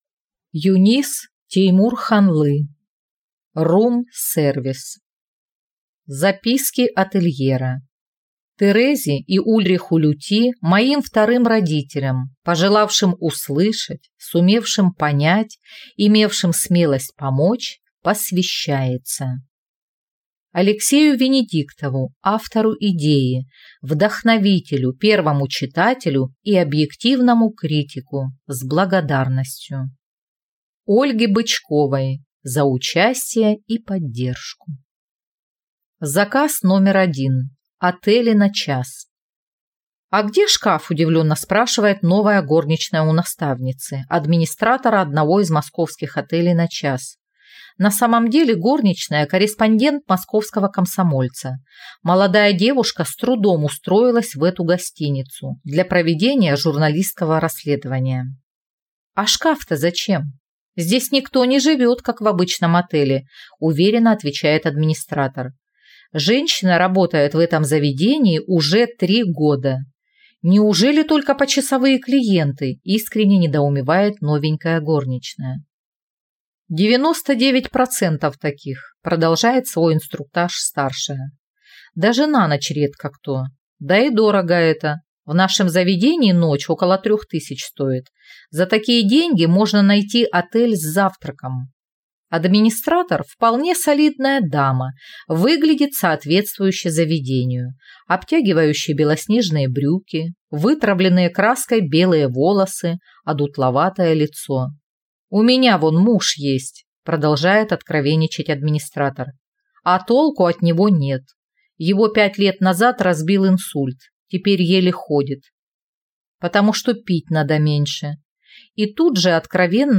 Аудиокнига «Room service». Записки отельера | Библиотека аудиокниг